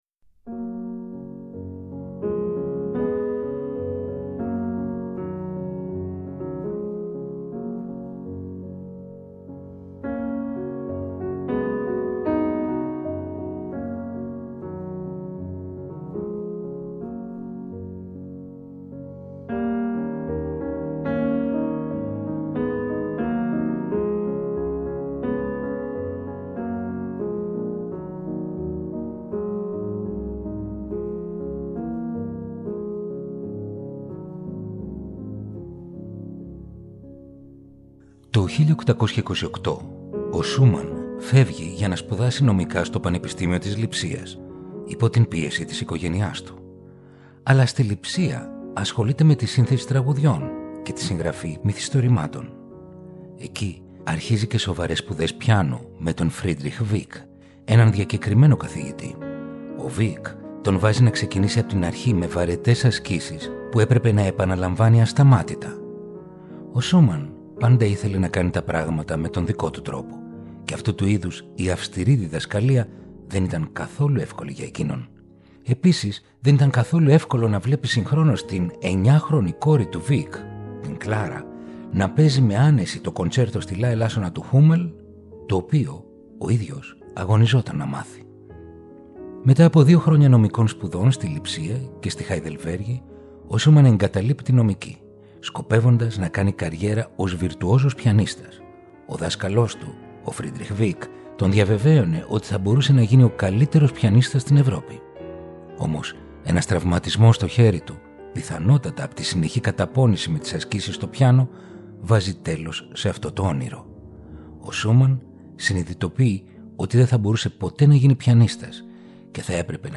Ρομαντικά κοντσέρτα για πιάνο – Επεισόδιο 8ο